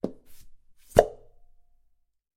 Оттягиваем присоску с нажимом